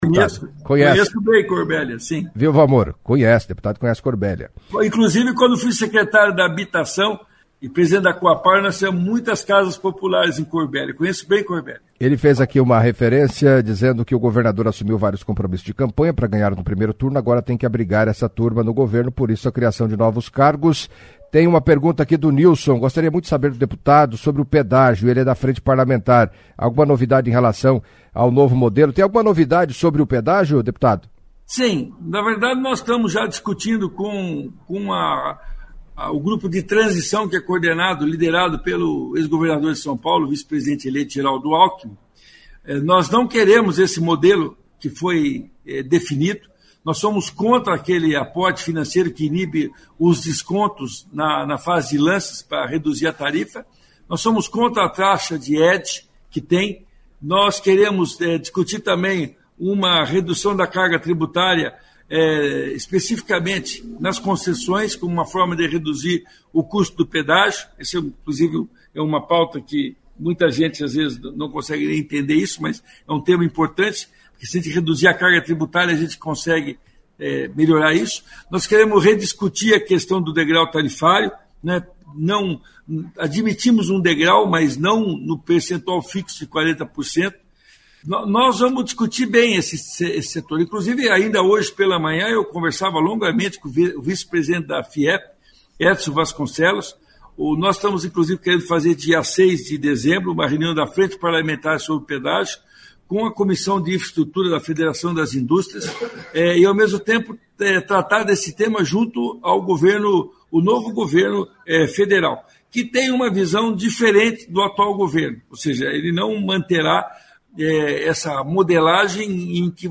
Em entrevista à CBN Cascavel nesta terça-feira (29) o deputado estadual Luiz Claudio Romanelli, do PSD, integrante da Mesa Diretora da Assembleia Legislativa, ocupa o cargo de 1º secretario, detalhou os projetos encaminhados pelo Executivo.